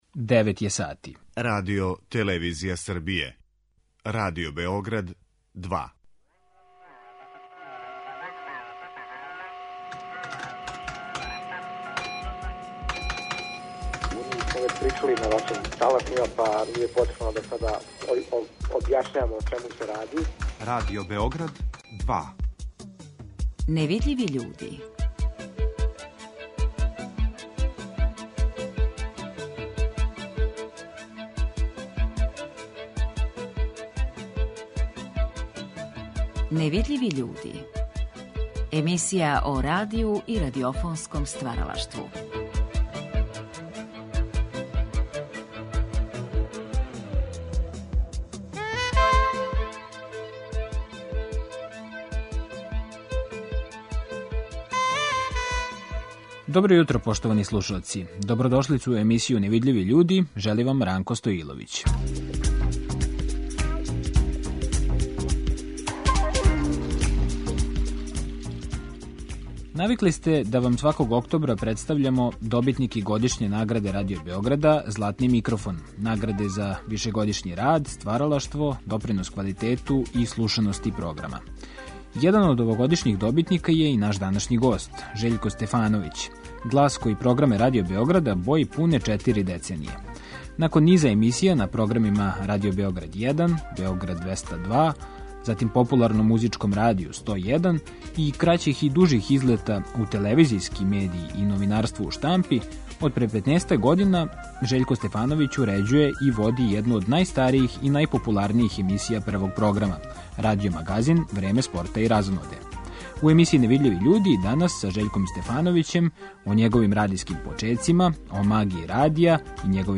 Чућете одломке ове емисије у којој је Драгослав Срејовић подробно говорио о свом највећем открићу - Лепенском виру.